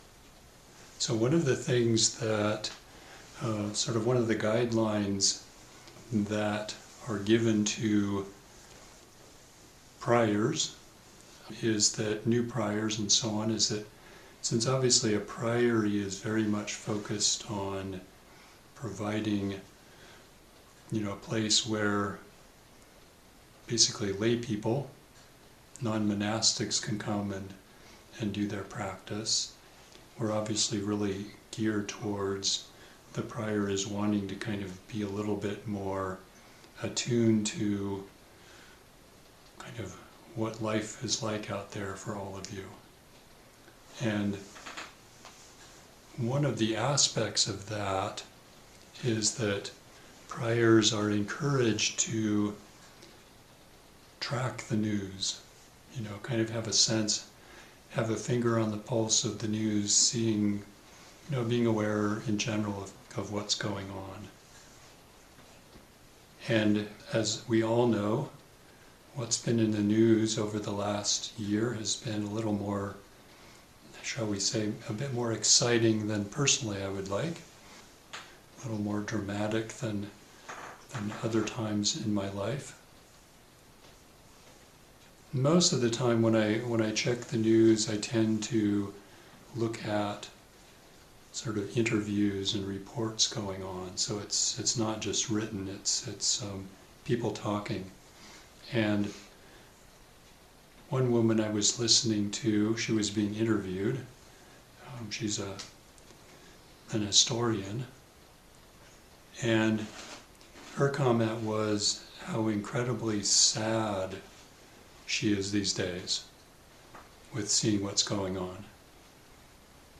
Recent Dharma talks
Feb-8-2026-Dharma-talk.mp3